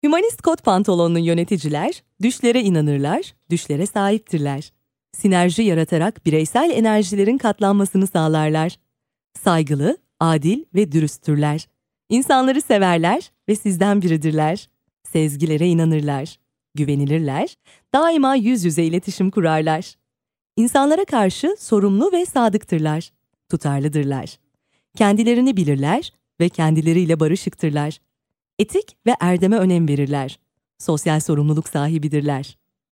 TR YB EL 01 eLearning/Training Female Turkish